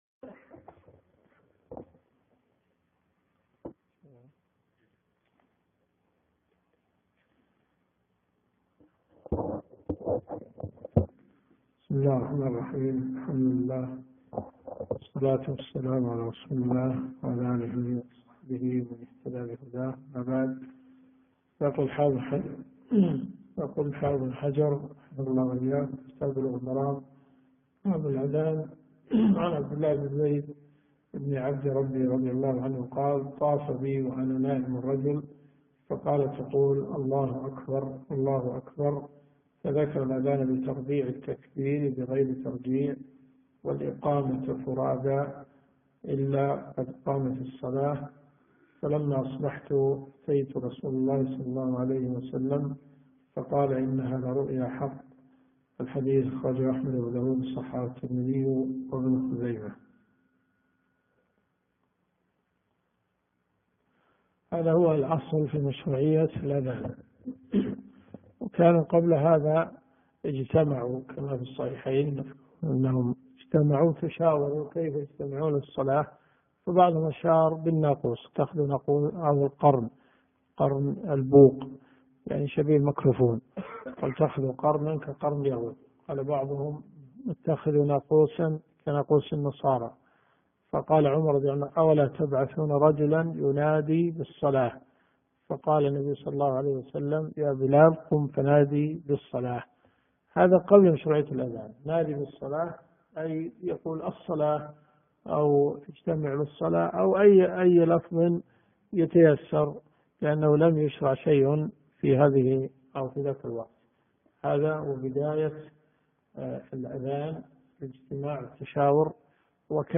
الرئيسية الكتب المسموعة [ قسم أحاديث في الفقه ] > بلوغ المرام .